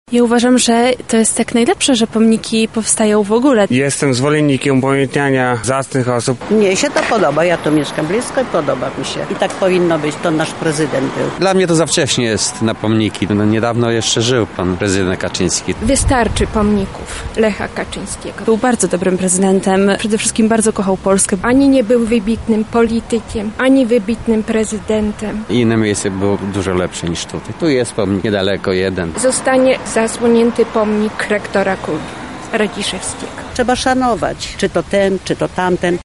SONDA: Nowy pomnik przy placu Teatralnym
Zapytaliśmy mieszkańców Lublina, co sądzą na temat monumentu.